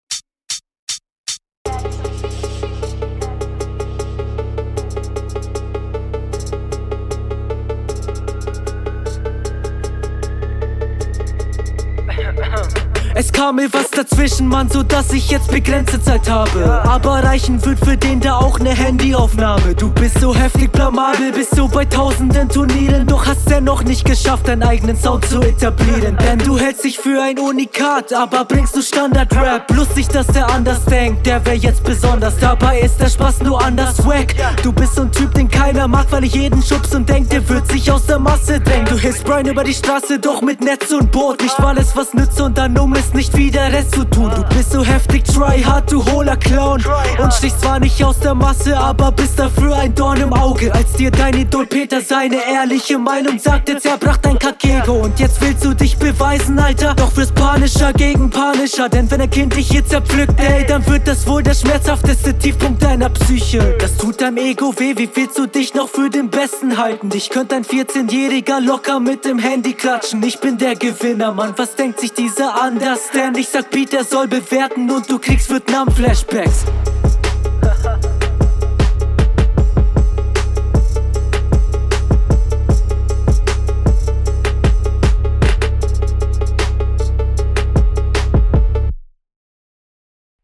Finde die Stimme und die Mische cool.